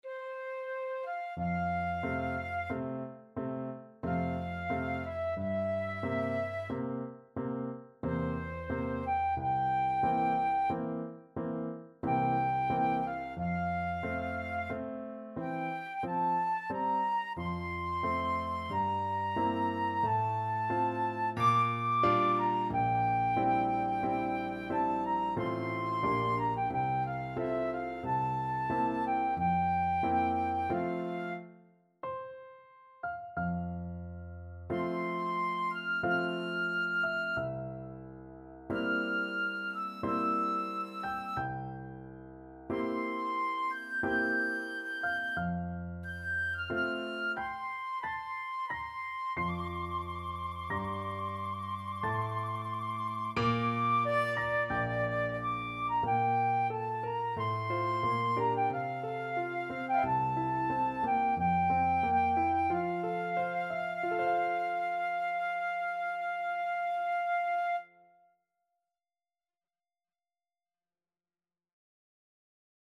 Free Sheet music for Flute
3/4 (View more 3/4 Music)
F major (Sounding Pitch) (View more F major Music for Flute )
Adagio =45
Flute  (View more Intermediate Flute Music)
Classical (View more Classical Flute Music)